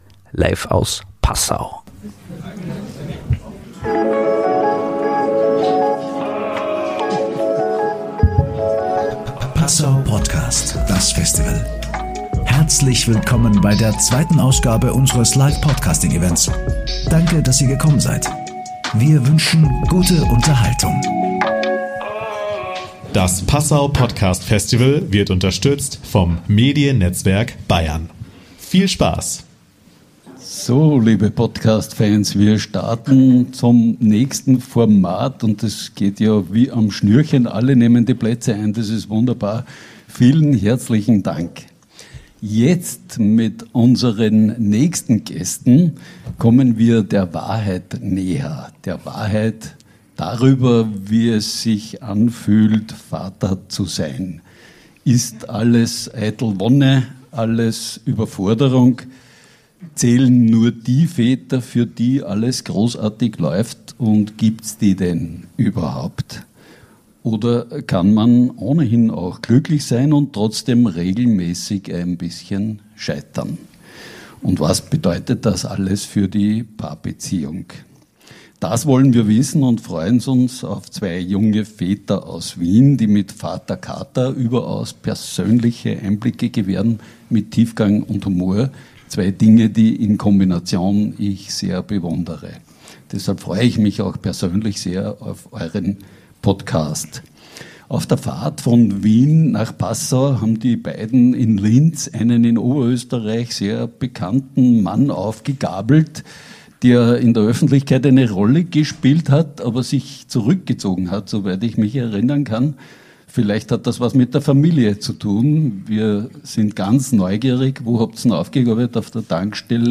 Sie waren zusammen mit dem Ex-Politiker Michael Lindner auf der Bühne beim Passau Podcast Festival 2025.